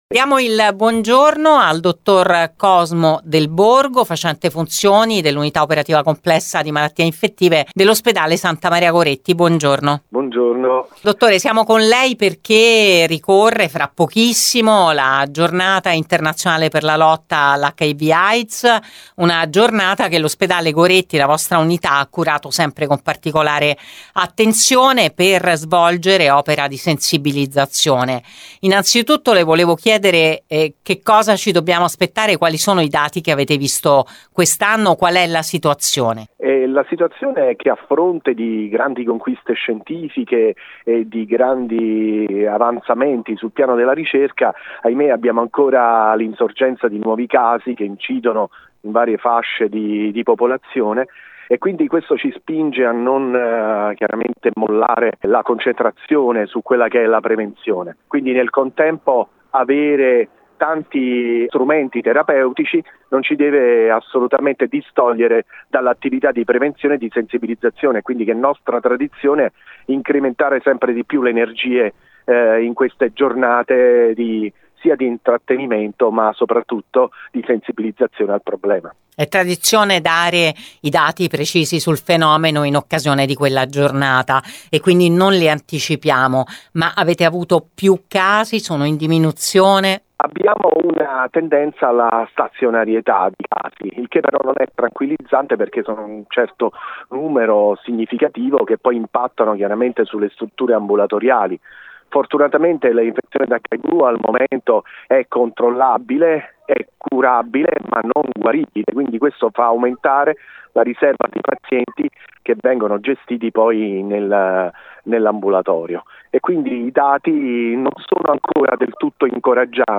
TUTTI I DETTAGLI NELL’INTERVISTA